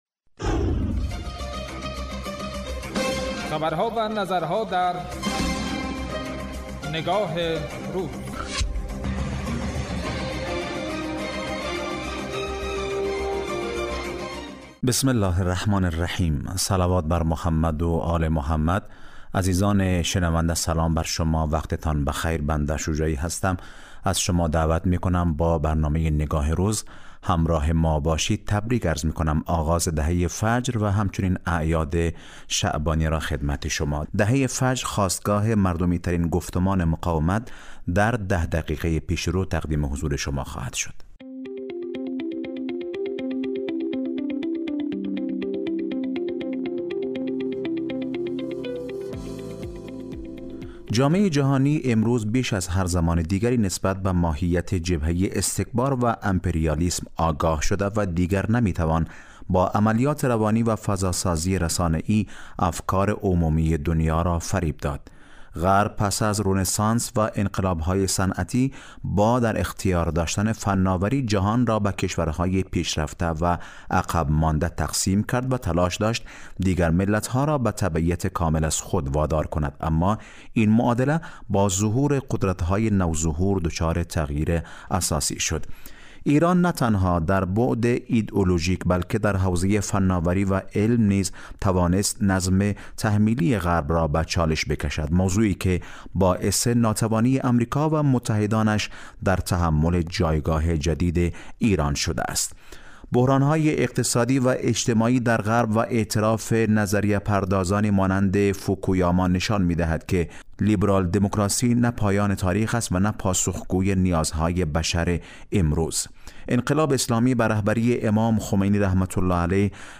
انقلاب اسلامی به رهبری امام خمینی(ره) نگاه تك‌ساحتی غرب را كنار زد و با آشتی دادن دین و زندگی، انسان را در ابعاد مادی و معنوی تعریف كرد؛ نگاهی كه امروز در سطح جهان بازتاب یافته و باعث نگرانی جدی نظام سلطه شده است. برنامه تحلیلی نگاه روز از شنبه تا پنجشنبه راس ساعت 14 به مدت ده دقیقه پخش می گردد